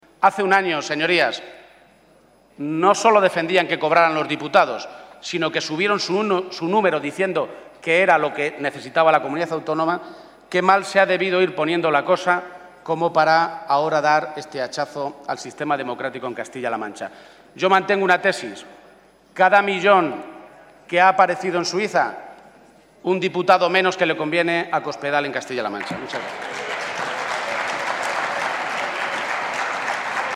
Emiliano García-Page durante su intervención en el Senado
Audio Page-primera intervención Senado 3